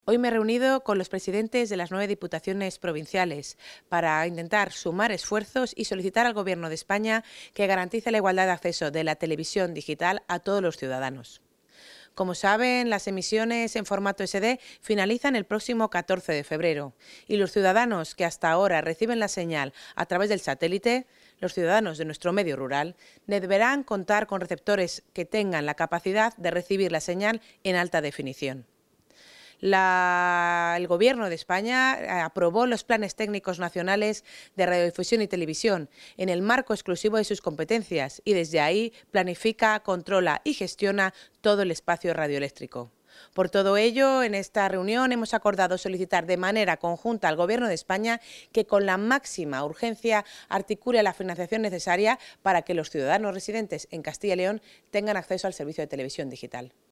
Valoración de la consejera.